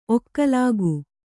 ♪ okkalāgu